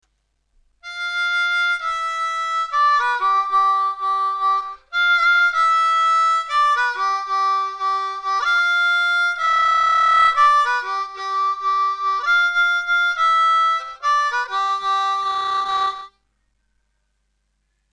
5D   5B 4D   3D..2D   2D   2D..2D (with hand vibrato)